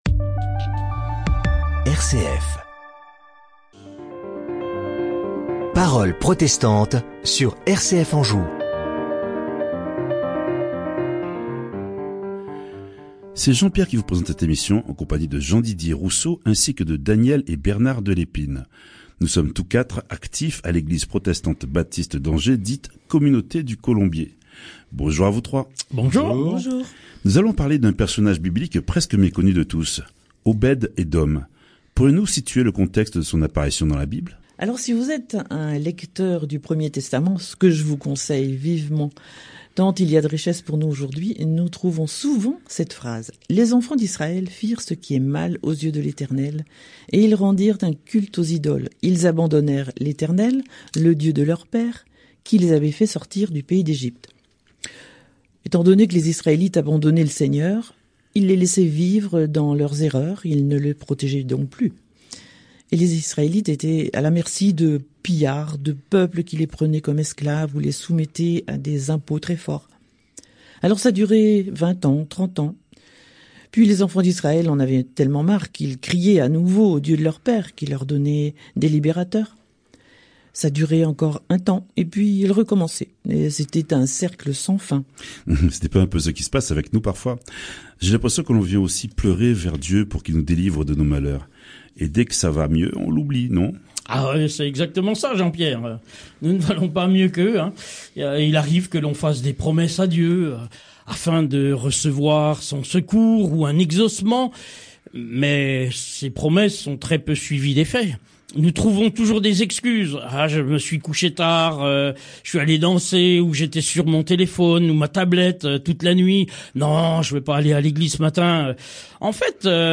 5 décembre 2021 L’extraordinaire vie de prière d’Obed-Edom, dit le béni Séries: Emission de radio RCF - Parole Protestante Type De Service: Podcast Obed-Edom, vous ne connaissez pas ?